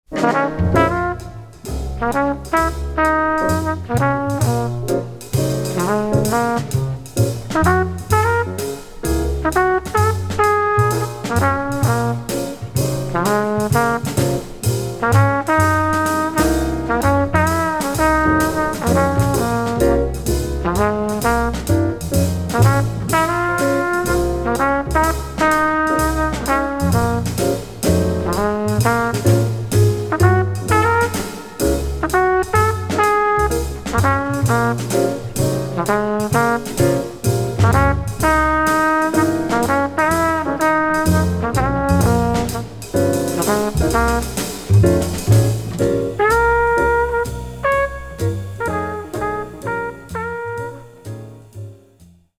A simple minor-key riff blues head.